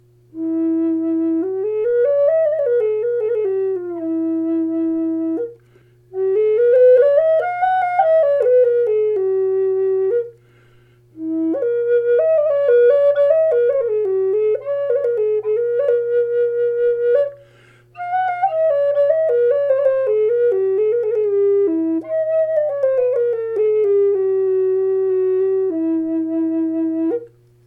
E4 in Curly Redwood with a Pepperwood bird